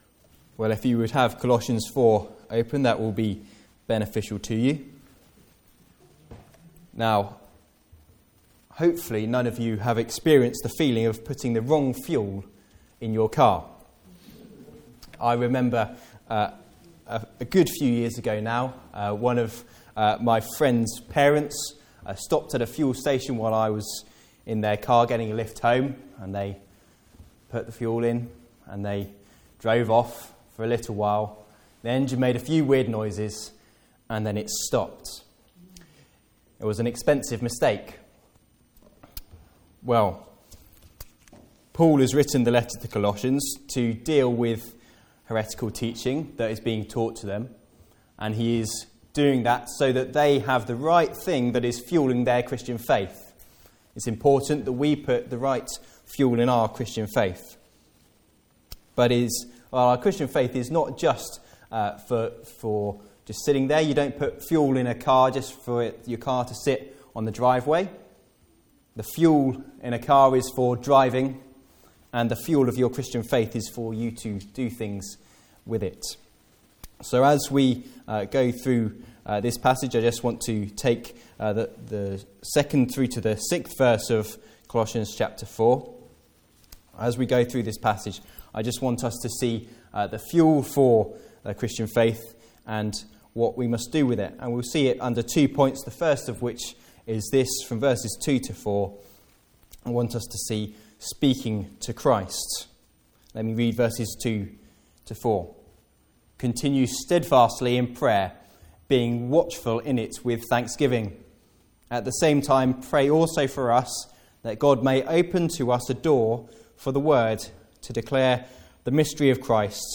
Passage: Isaiah 2: 1-11 Service Type: Afternoon Service